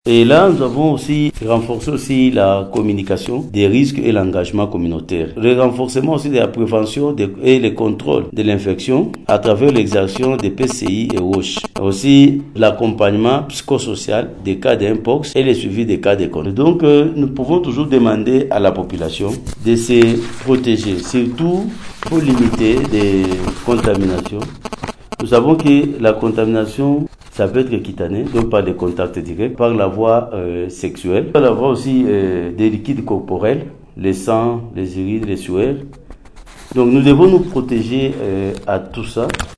Précision du ministre provincial de la santé docteur Theophile Walulika dans un entretien avec Radio Maendeleo jeudi 22 aout 2024.